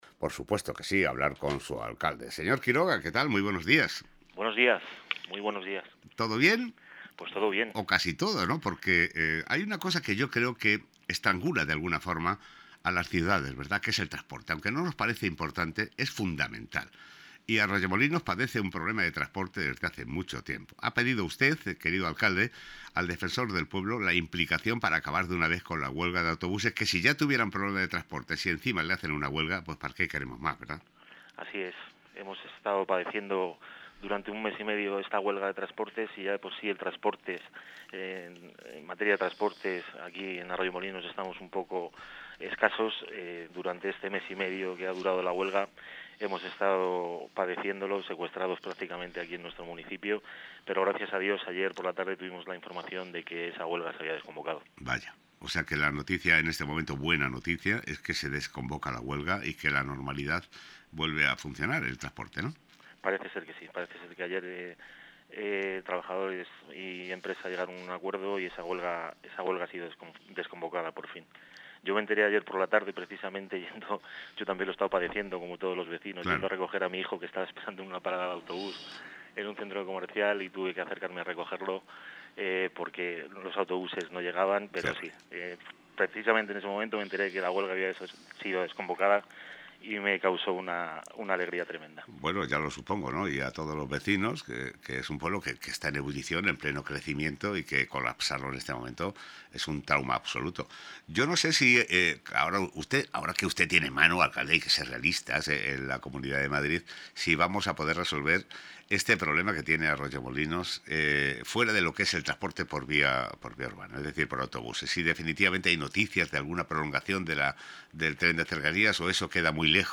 Entrevista a Luis Quiroga, Alcalde de Arroyomolinos
Con respecto a la huelga de transportes en el municipio de Arroyomolinos, el Alcalde, Luis Quiroga ha realizado unas declaraciones en el programa matinal de GloboFM